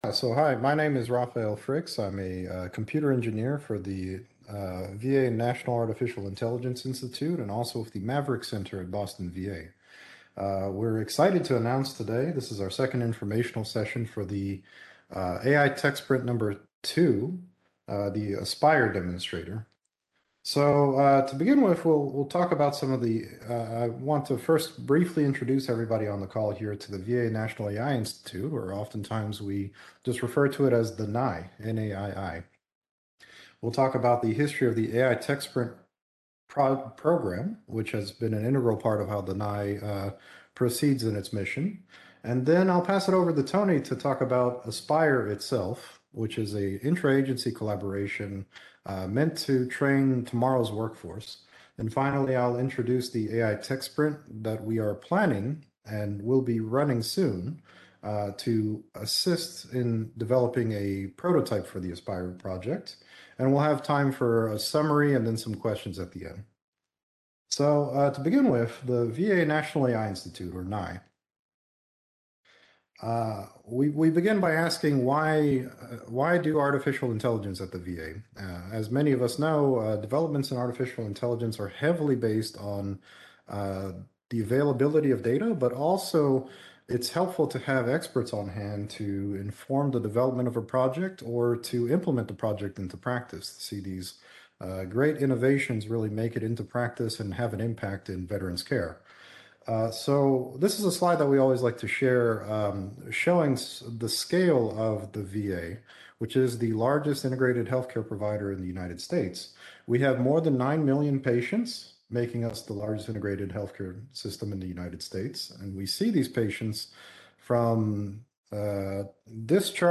PhD Seminar date